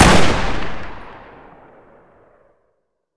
wpn_trailcarbine.wav